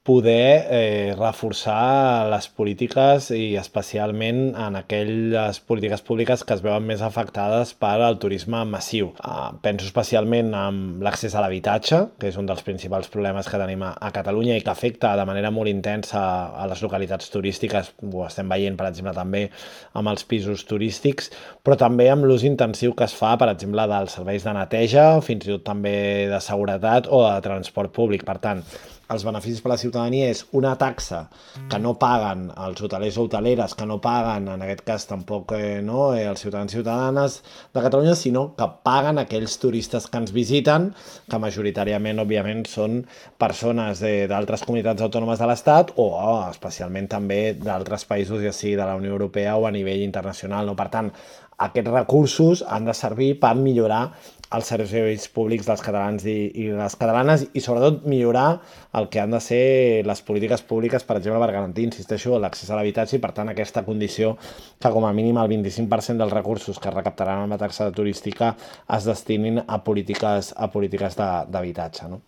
El portaveu del grup parlamentari dels Comuns, David Cid, assegura a Ràdio Capital de l’Empordà que “la taxa turística serveix per reforçar les polítiques públiques que es veuen afectades pel turisme massiu”.